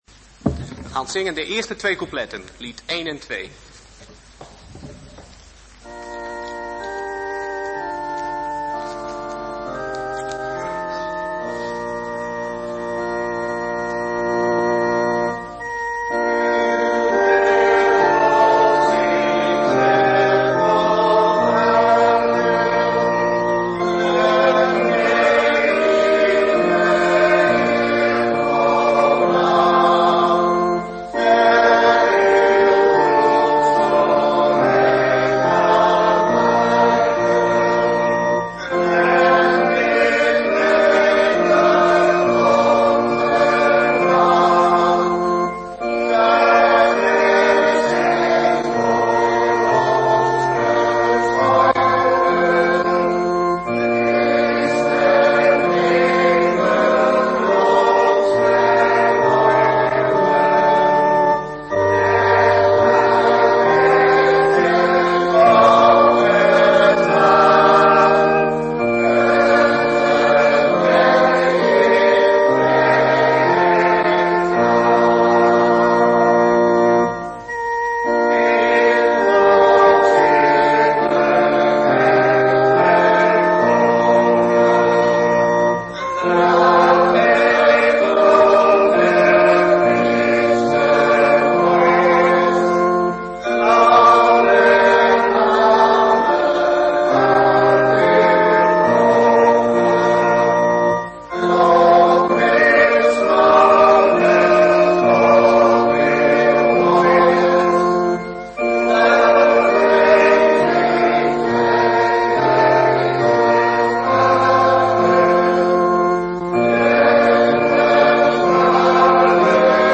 Een preek over 'Verlossing'.